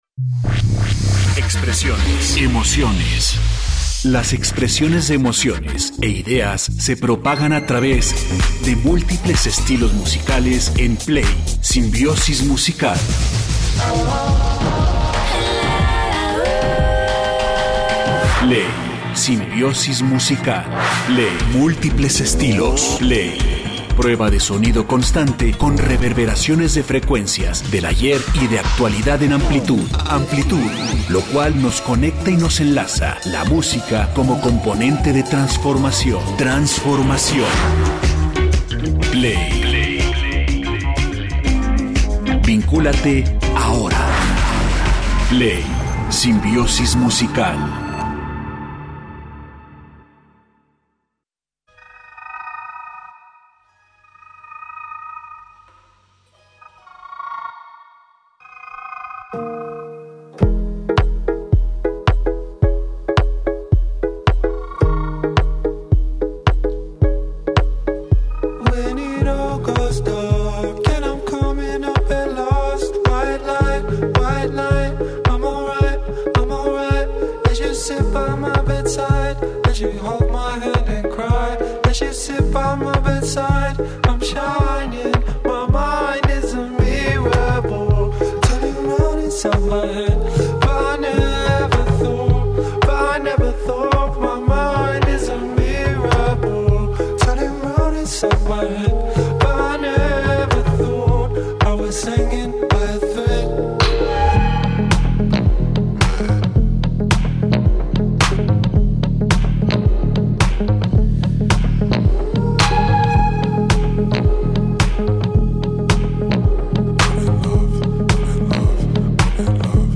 Vincúlate ya al 104.3FM y escucha la amplia selección musical